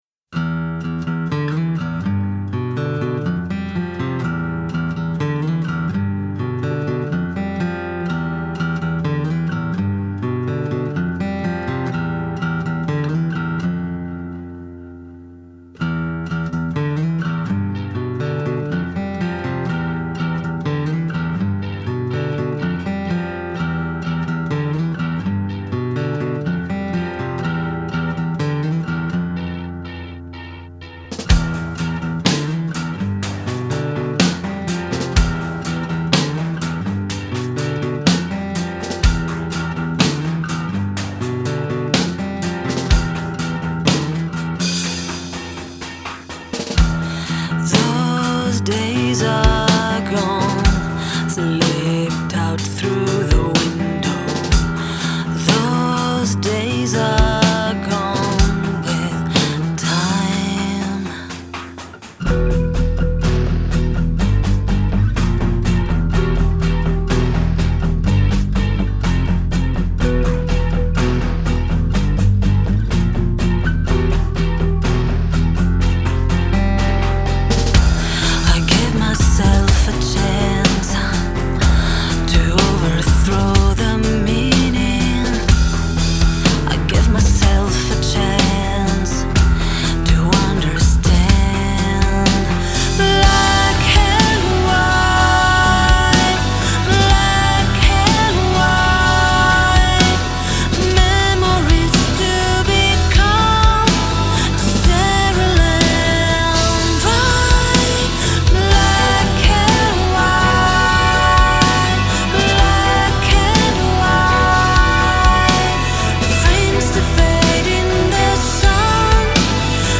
Vocals
Guitars, Keyboard, Programming
Drums
Bass